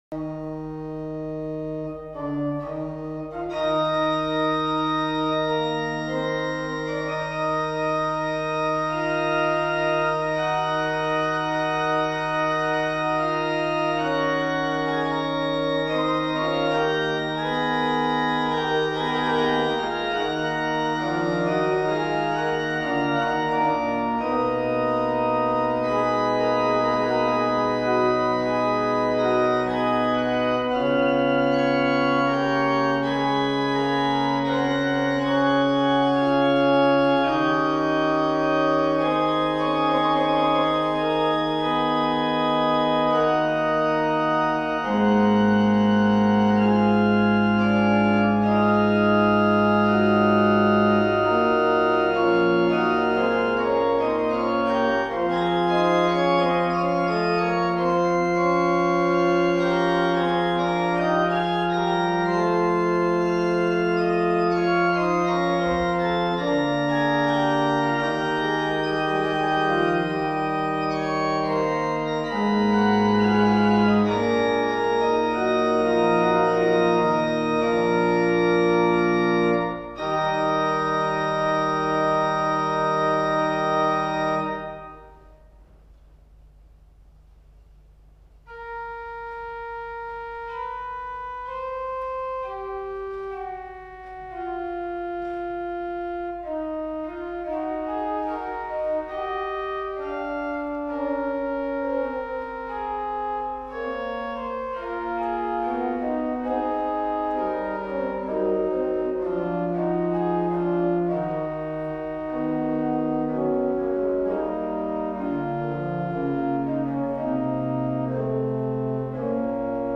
Med hjälp av instrumentet kan vi spela den tidens repertoar på ett instrument som har den tidens finesser och klingar enligt den tidens ideal.
Jag spelar Toccata avanti il Recercar och Recercar Cromaticho post il Credo från Messa delli Apostoli ur Fiori Musicali av Girolamo Frescobaldi (1583-1643), inspelat vid en konsert i Norrfjärdens kyrka januari 2008.